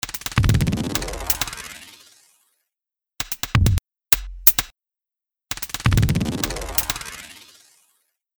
H910 Harmonizer | Percussion | Preset: Shimmer Delay
H910-Harmonizer-Eventide-Percussive-Element-Shimmer-Delay.mp3